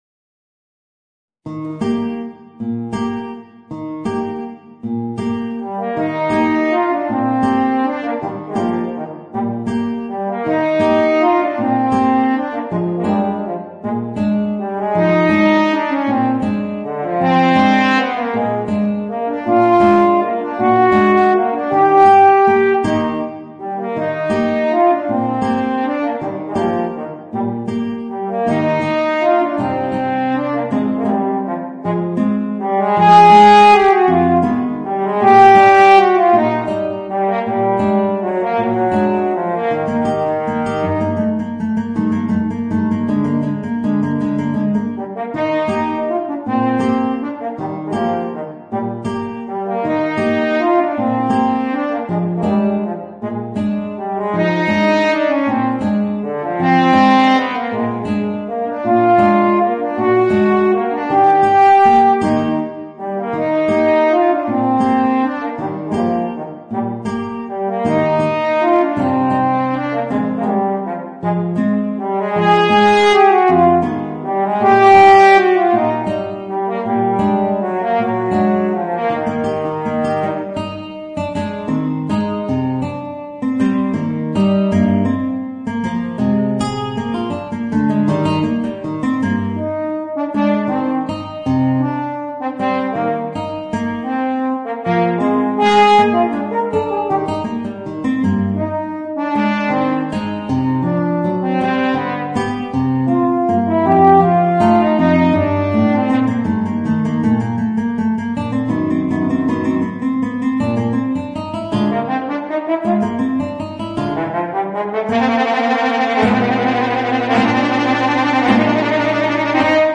Voicing: Guitar and Eb Horn